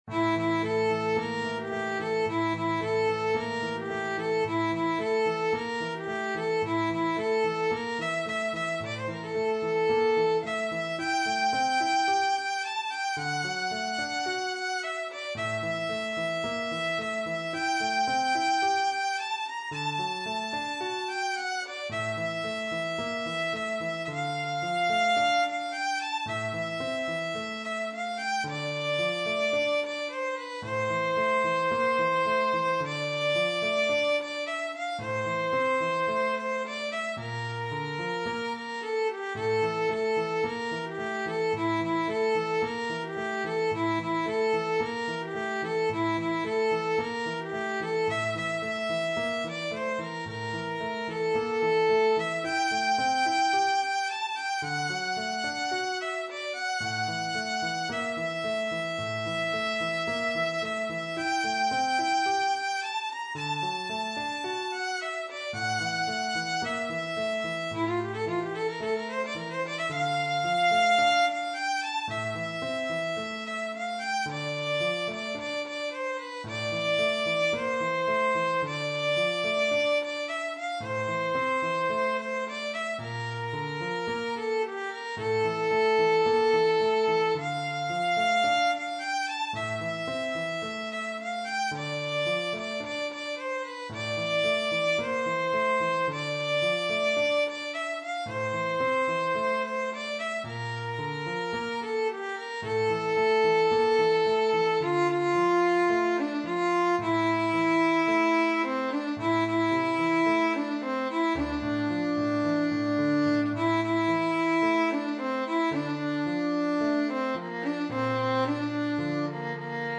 سطح : متوسط
ویولون